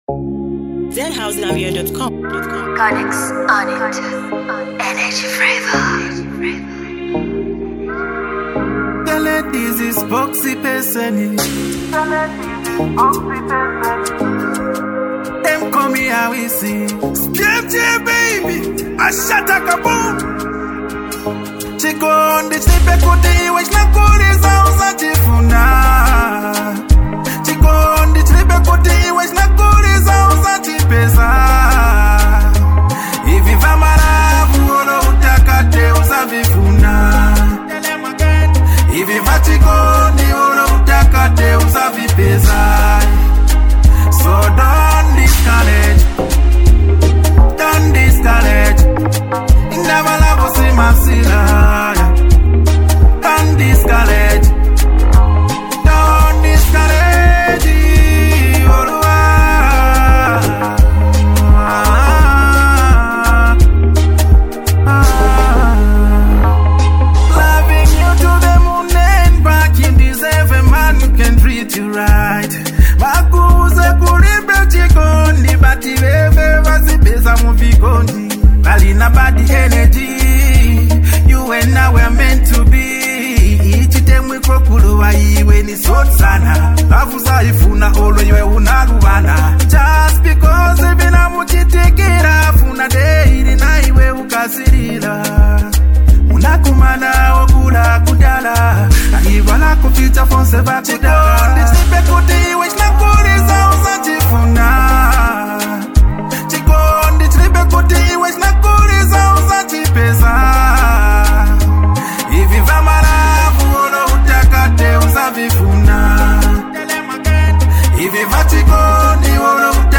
a heartfelt track